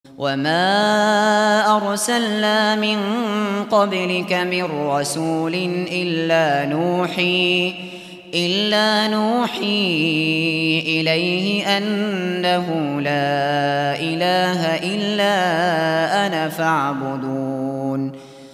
Hafız Abu Bakr al Shatri sesinden 21/ENBİYÂ-25 dinle!